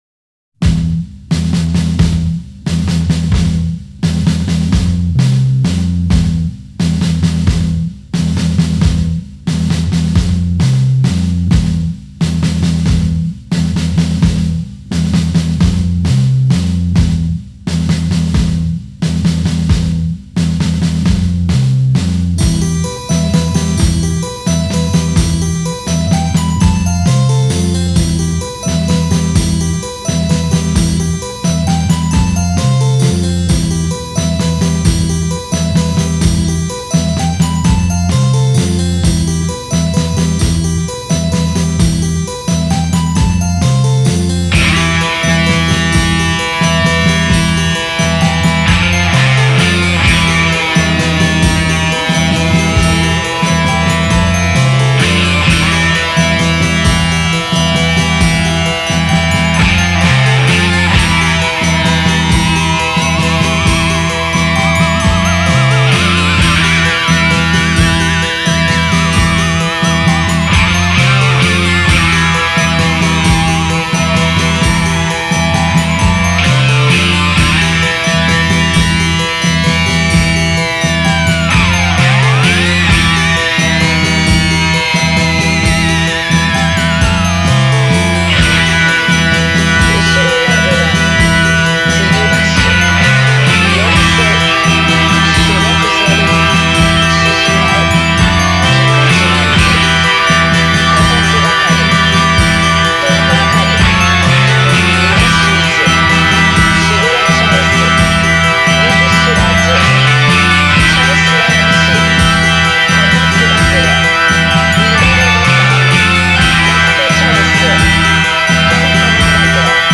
Genre: Psychedelic Rock, Garage